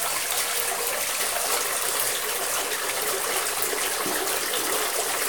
bath4.ogg